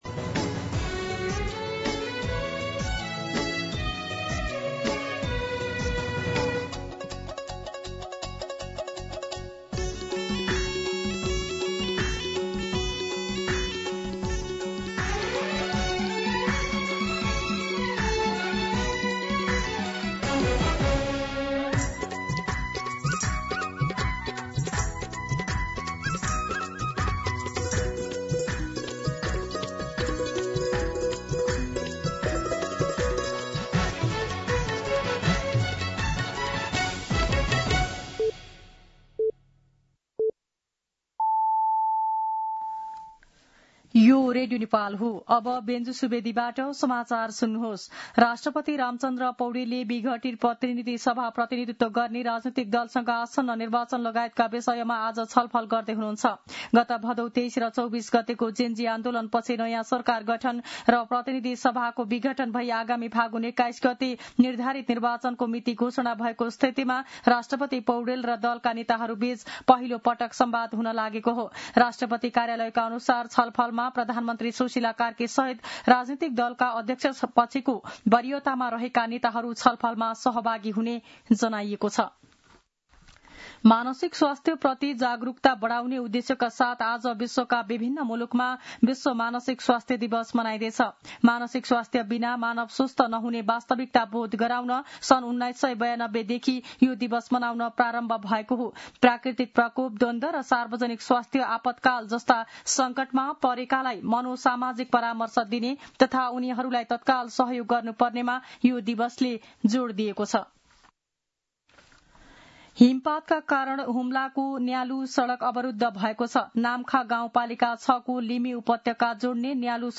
दिउँसो १ बजेको नेपाली समाचार : २४ असोज , २०८२